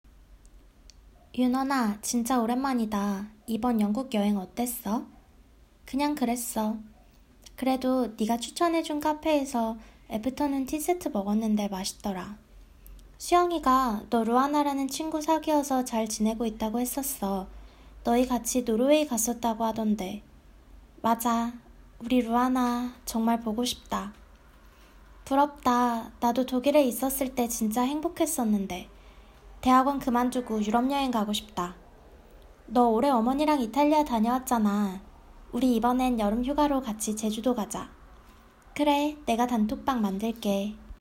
6_Conversation_A.m4a